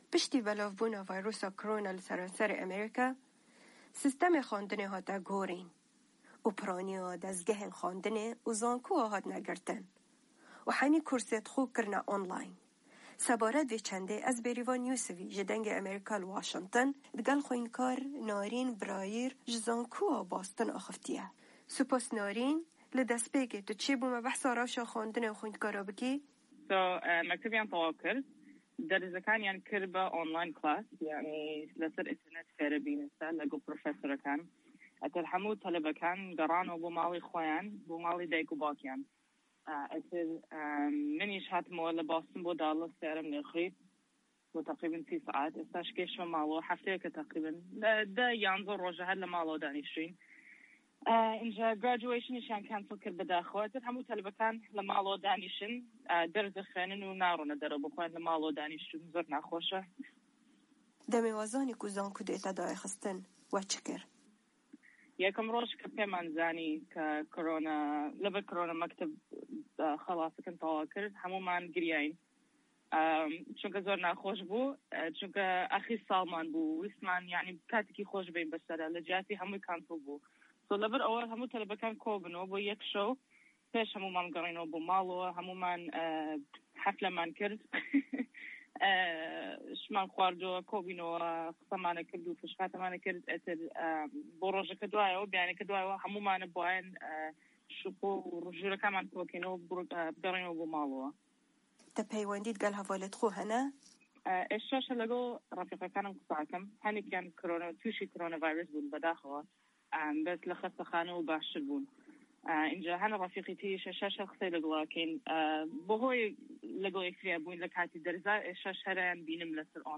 درێژەی وتووێژەکە: